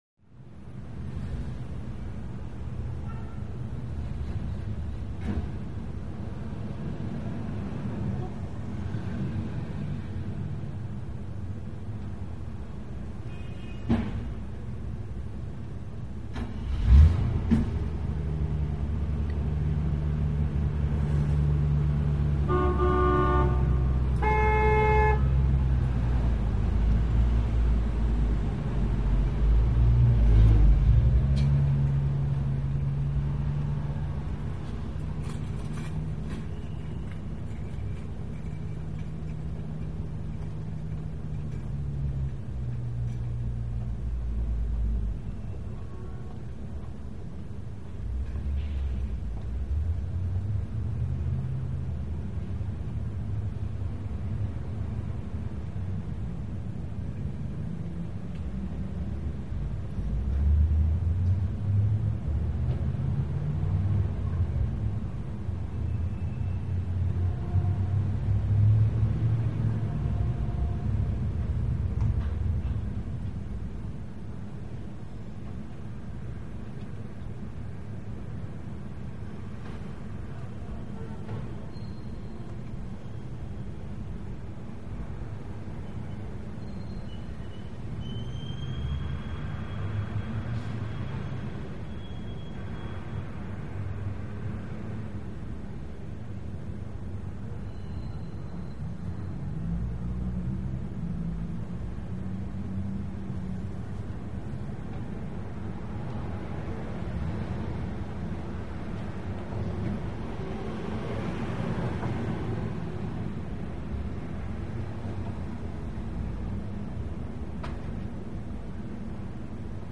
TrafficInteriorPOV CT051801
Traffic, Interior Point Of View, Open Window A Few Floors Above The Street. Medium Busy With Horn Honks Medium To Distant, Brake Squeaks Truck By, Doors Open And Close, Movement.